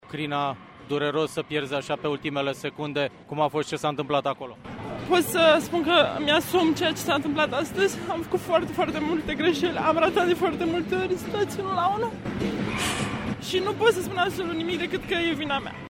în lacrimi.